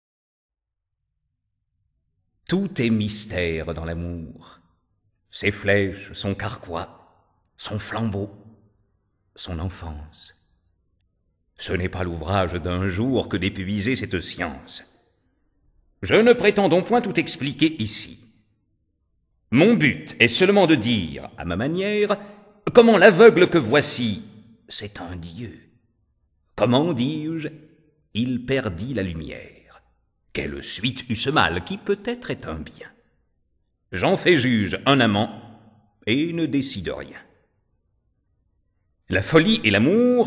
C'est aussi la rencontre entre deux interprètes: l'un est comédien, l'autre claveciniste